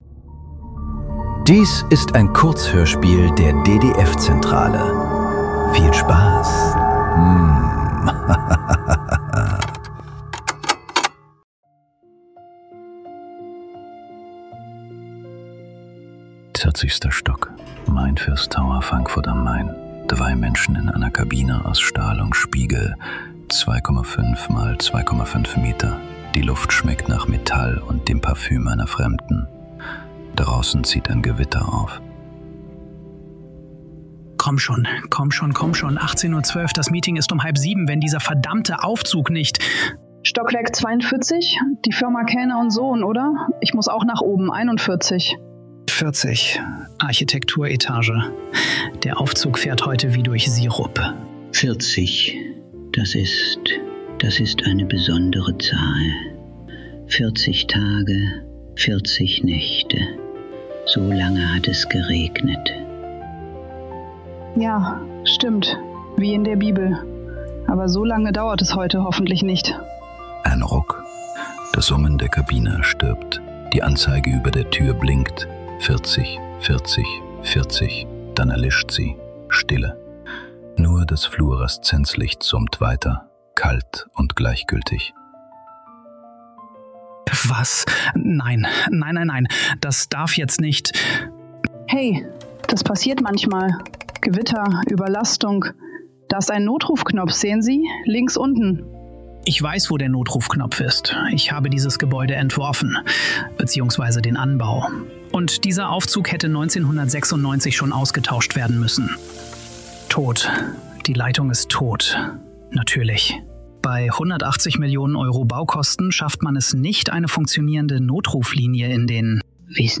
Kurzhörspiele.